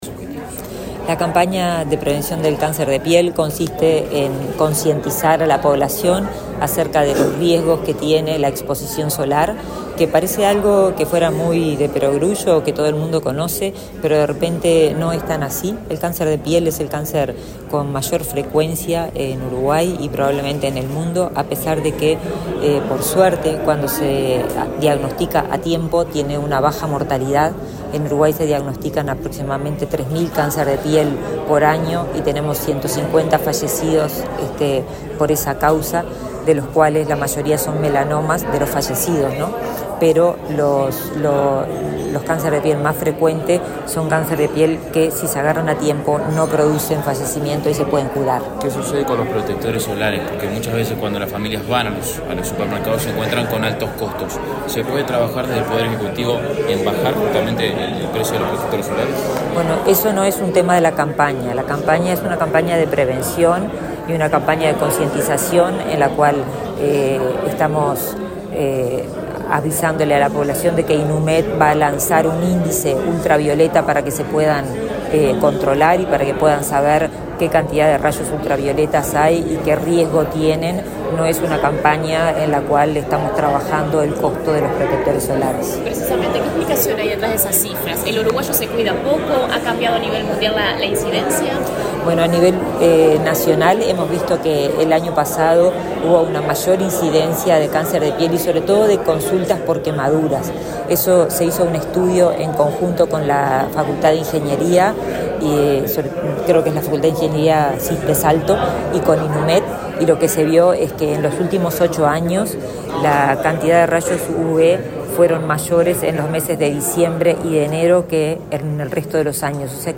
Declaraciones de la ministra de Salud Pública, Karina Rando
La ministra de Salud Pública, Karina Rando, dialogó con la prensa, luego de participar del lanzamiento de la campaña de prevención de cáncer de piel,